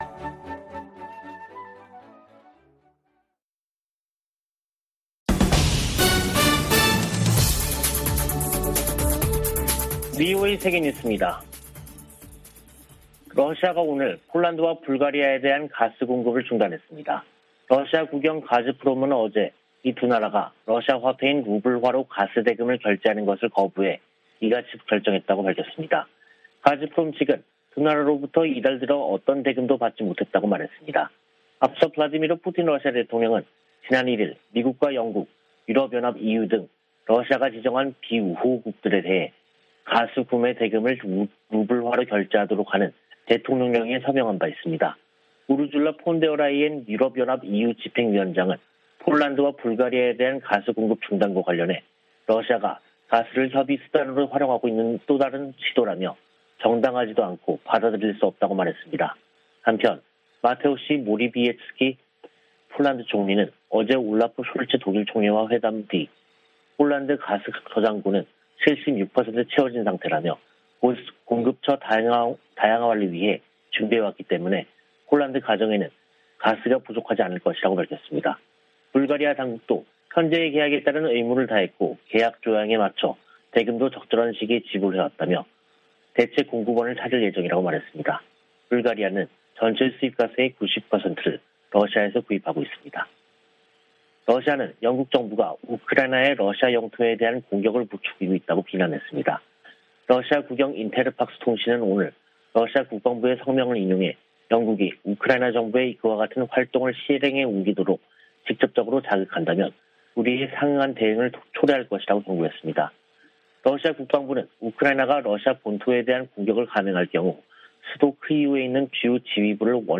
VOA 한국어 간판 뉴스 프로그램 '뉴스 투데이', 2022년 4월 27일 2부 방송입니다. 미 국무부는 ‘핵무력’을 언급한 김정은 북한 국무위원장의 25일 연설에 대해 북한이 국제 평화와 안보에 위협이 되고 있다고 지적했습니다.